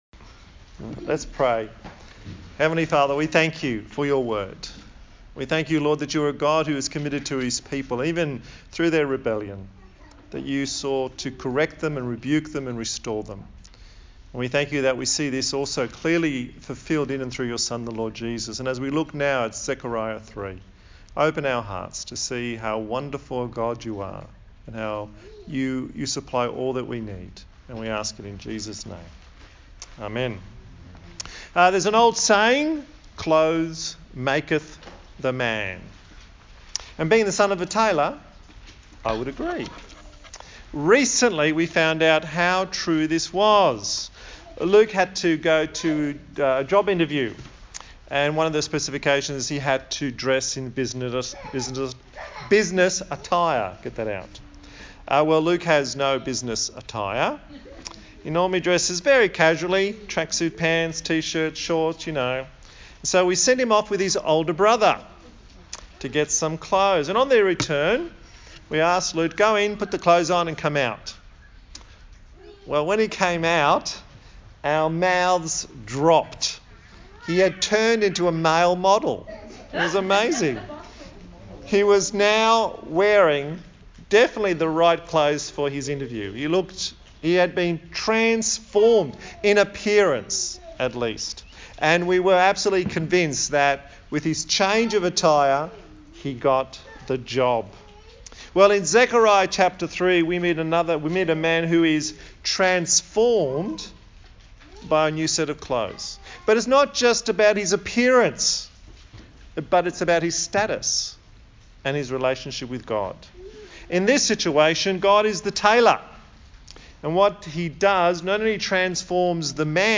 A sermon in the series on the book of Zechariah